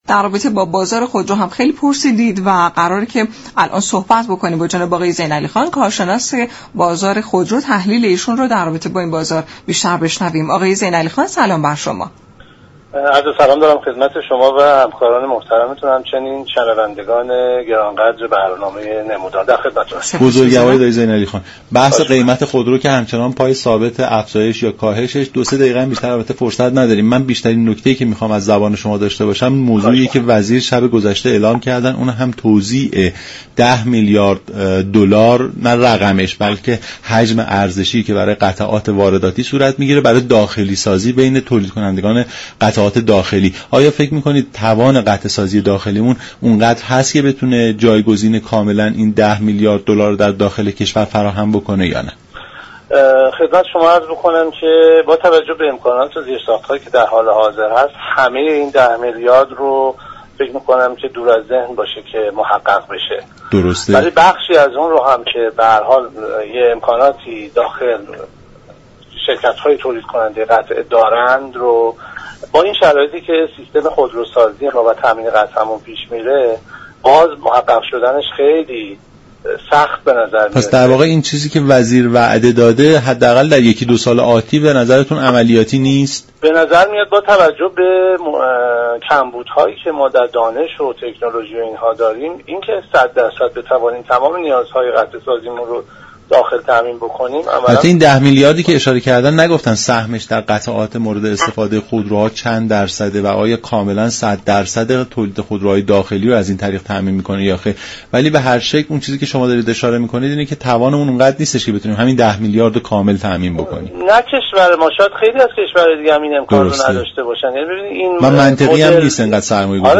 به گزارش شبكه رادیویی ایران، یك كارشناس خودرو در گفت و گو با برنامه «نمودار» رادیو ایران درباره خبر اختصاص ده میلیارد دلار برای داخلی سازی قطعات خودروهایی كه از خارج وارد می شوند، به شركت های خودروساز گفت: ایران با توجه به حجم دانش و تكنولوژی خود، توان استفاده از ده میلیاد دلار را ندارد.